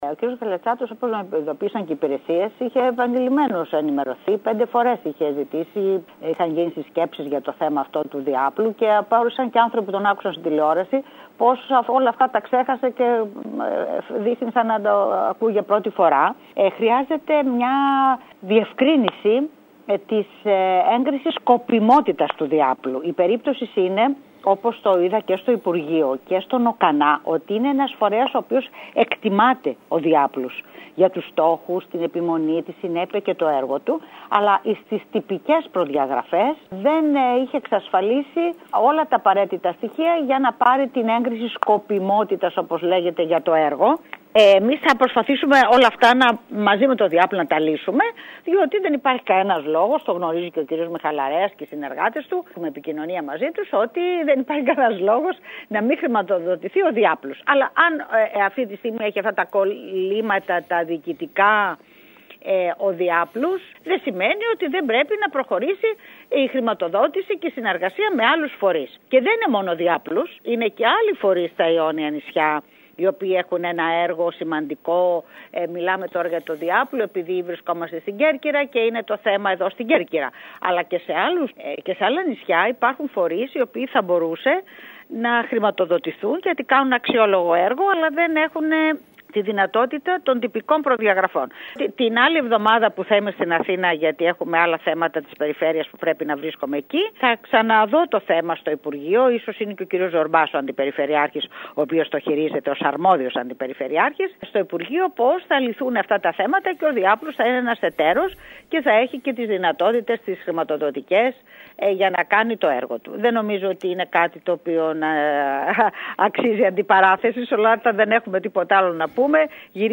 Θα ενταχθεί στις χρηματοδοτήσεις η μονάδα του ΔΙΑΠΛΟΥ αφού όμως τακτοποιηθούν διοικητικού χαρακτήρα εκκρεμότητες δηλώνει η Περιφερειάρχης Ρόδη Κράτσα μιλώντας σήμερα στην ΕΡΑ ΚΕΡΚΥΡΑΣ. Όπως είπε χρειάζεται έγκριση σκοπιμότητας του ΔΙΑΠΛΟΥ πράγμα για το οποίο ήδη βρίσκεται σε συνενόηση τόσο με την ίδια τη μονάδα όσο και το Υπουργείο Υγείας και τον ΟΚΑΝΑ.